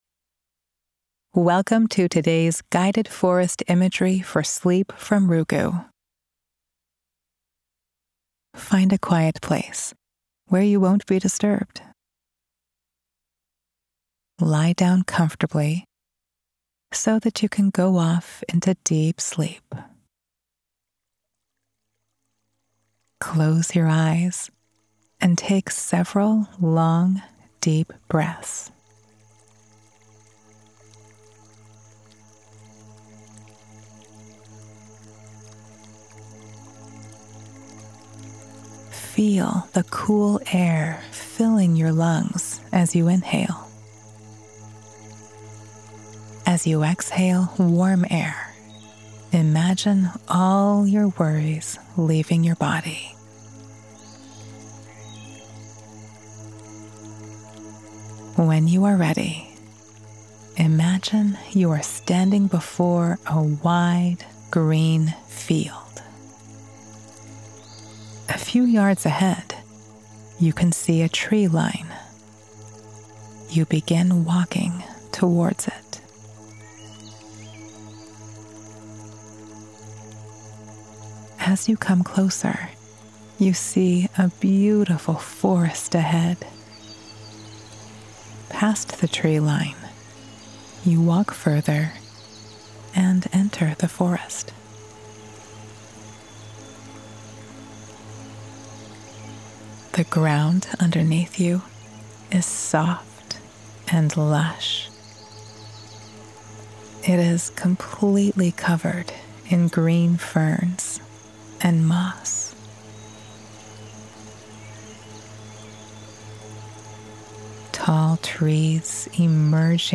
Guided Imagery Meditation